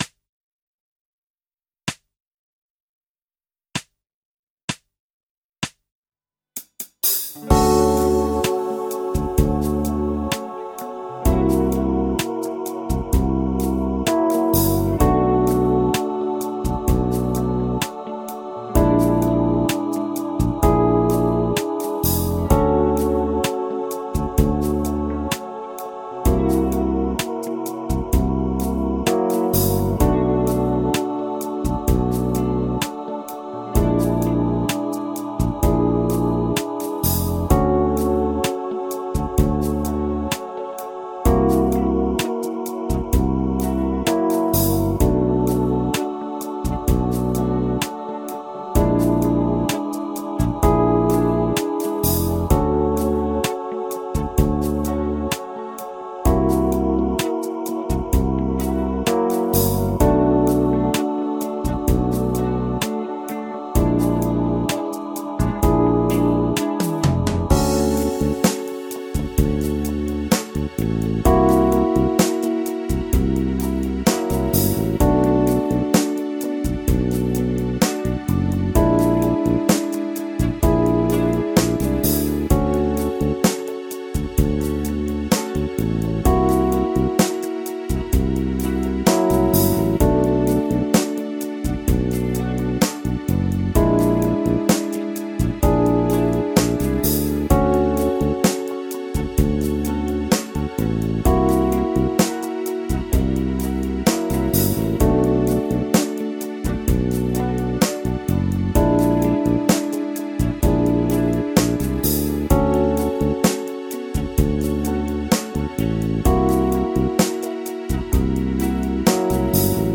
リディアン・ドミナント7thスケール ギタースケールハンドブック -島村楽器